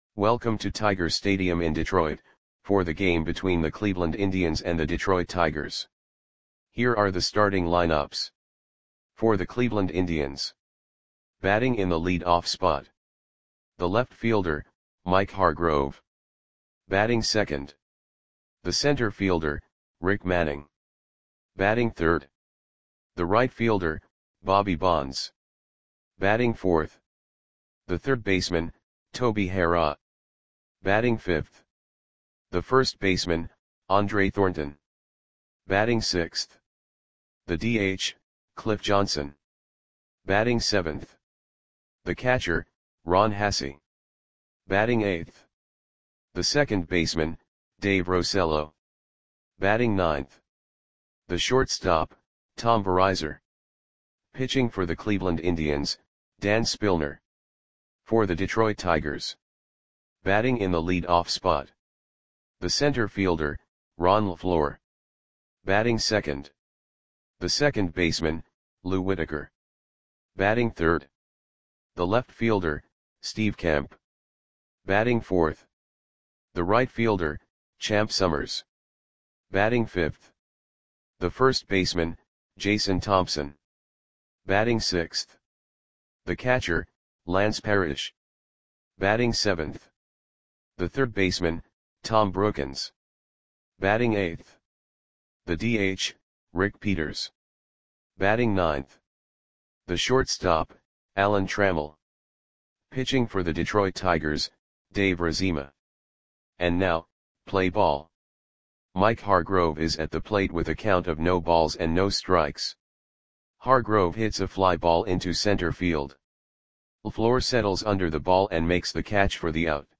Audio Play-by-Play for Detroit Tigers on September 11, 1979
Click the button below to listen to the audio play-by-play.